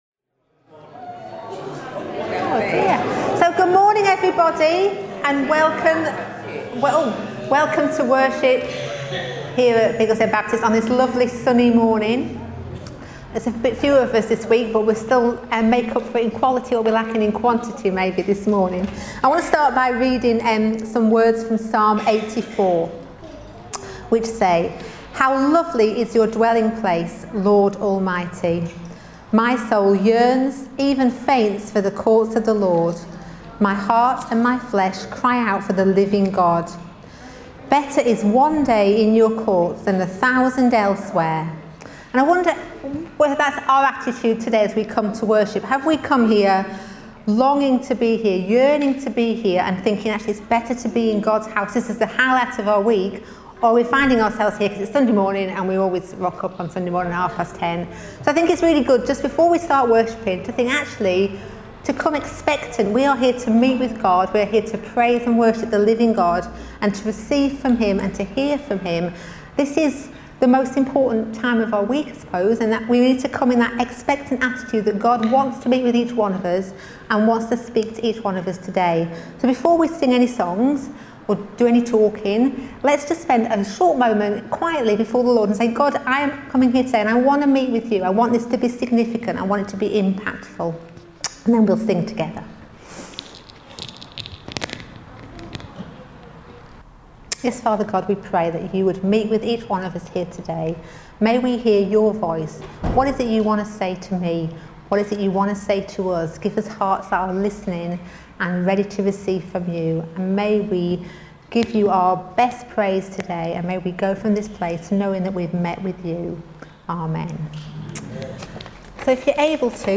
17 August 2025 – Morning Communion Service